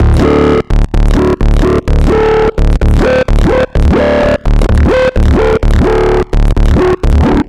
Session 11 - Bass 02.wav